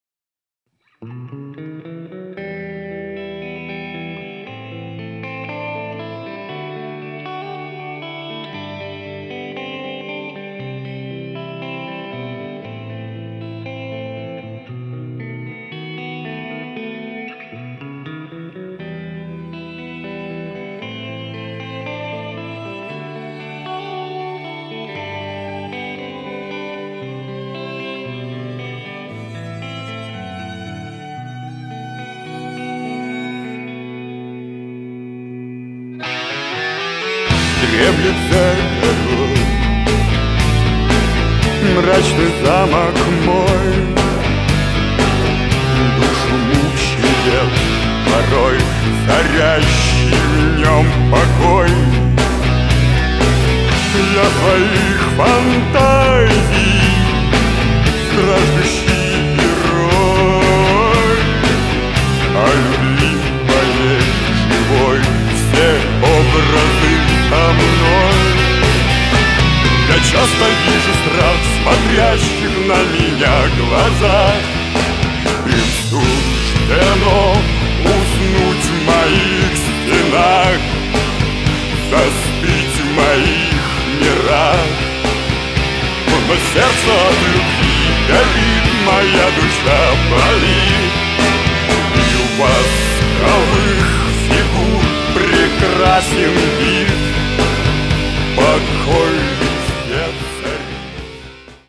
• Качество: 320, Stereo
мужской вокал
грустные
спокойные
электрогитара
фолк-панк
альтернативный метал
хард-рок
хоррор-панк